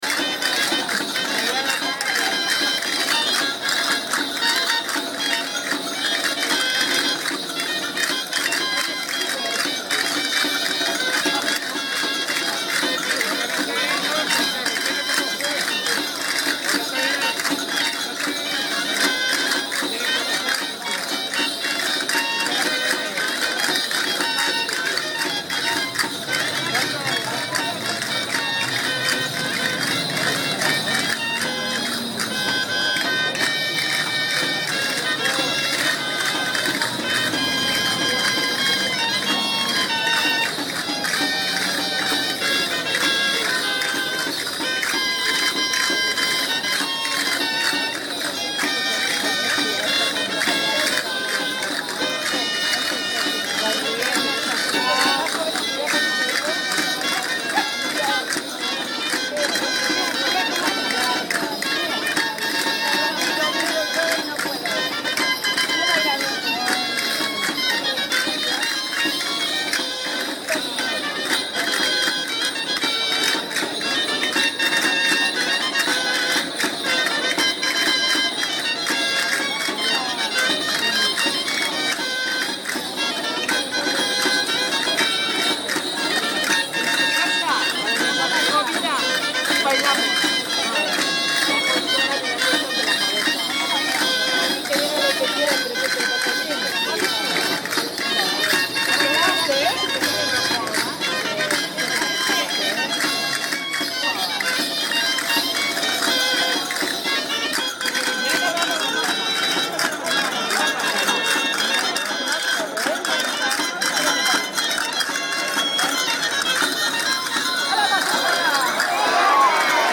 San Lorentzoko Gaiteroak Danzas de San Lorenzo.
Jota.